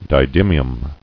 [di·dym·i·um]